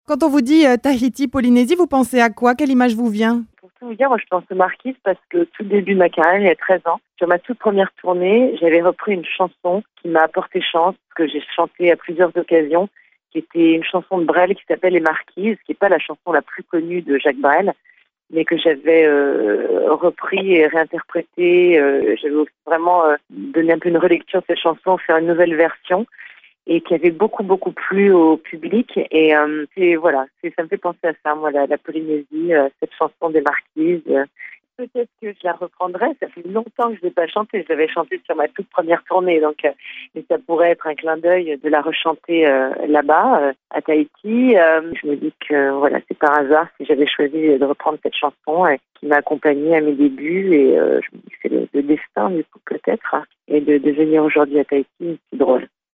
Quelques heures après son arrivée, Radio 1 l’a rencontré pour en savoir davantage sur le concert qu’elle donnera mais aussi sur cette artiste à la fois humble et talentueuse.